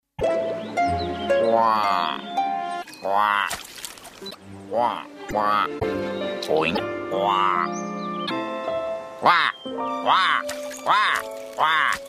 Дети прослушивают голоса героев мультфильмов.